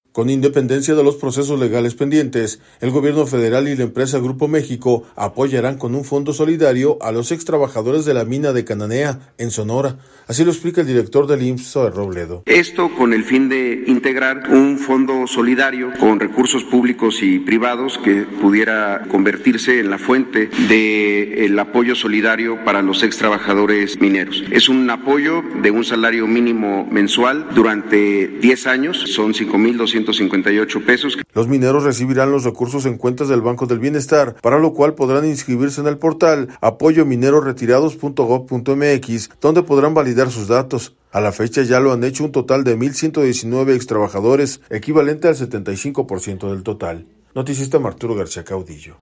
Con independencia de los procesos legales pendientes, el Gobierno Federal y la empresa Grupo México apoyarán con un fondo solidario a los ex trabajadores de la mina de Cananea, en Sonora, así explica el director del IMSS, Zoé Robledo.